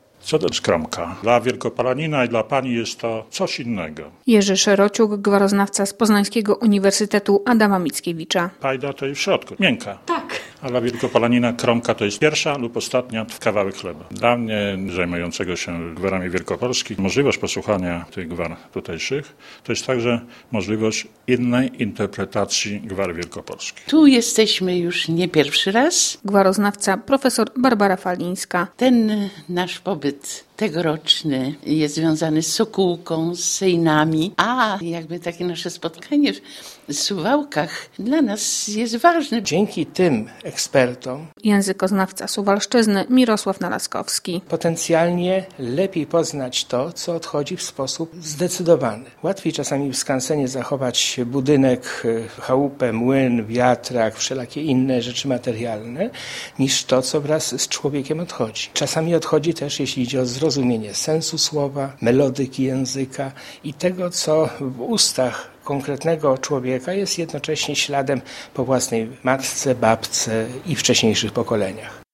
Konferencja gwaroznawcza - relacja